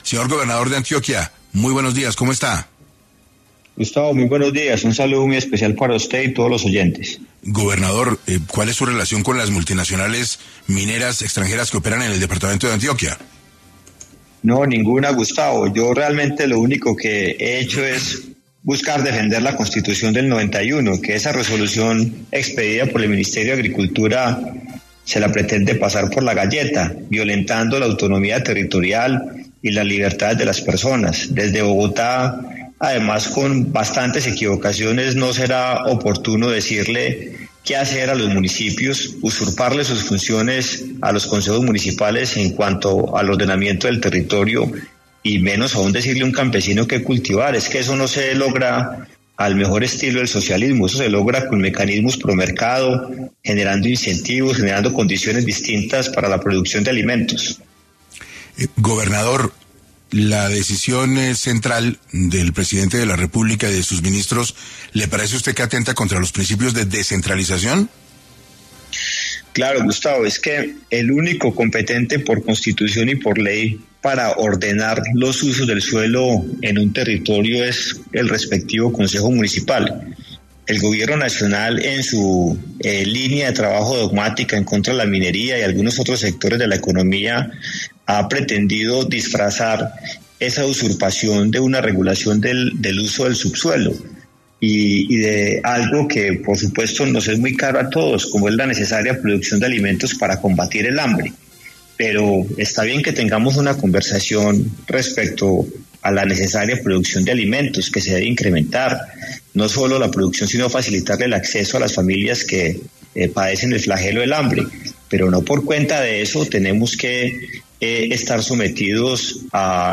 En 6AM de Caracol Radio estuvo Andrés Julián Rendón, gobernador de Antioquia, para hablar sobre los señalamientos de Gustavo Petro por supuestamente favorecer a multinacionales mineras en medio de debate del uso del suelo.